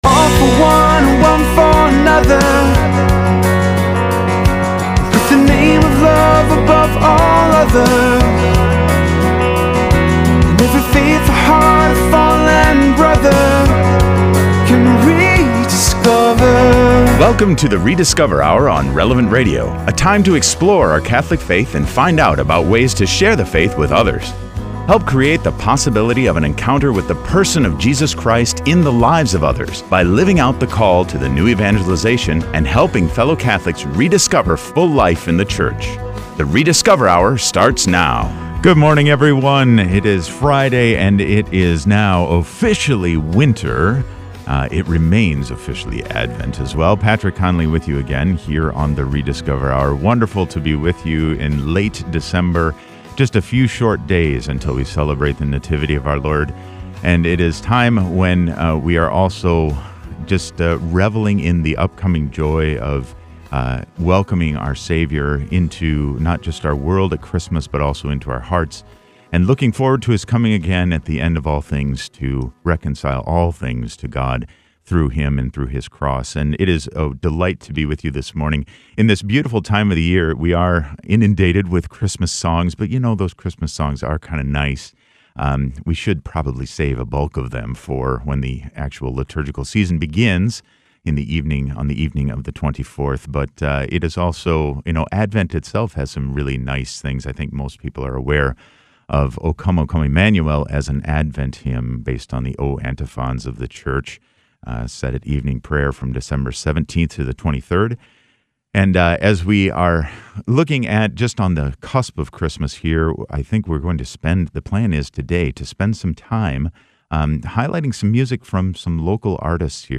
música de Adviento y Navidad
una actuación en directo en el estudio